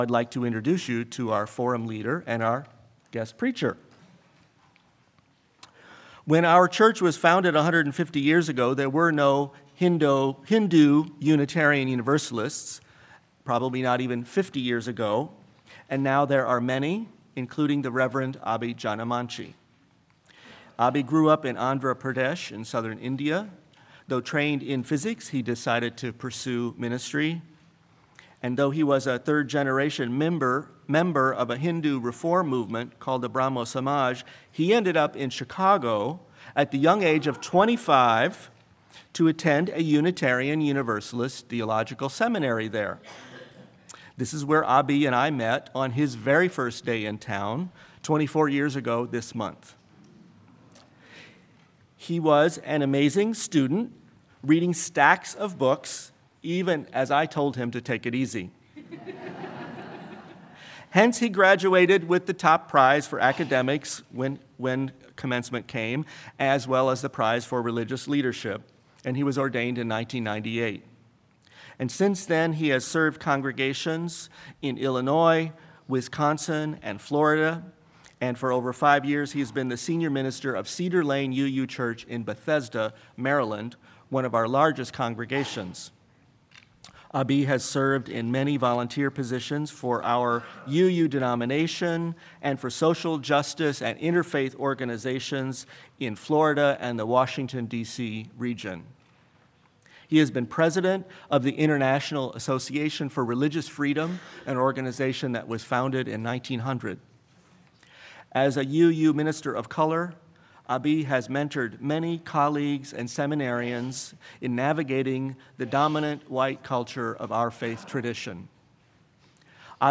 Sermon-Engaging-with-Hinduism.mp3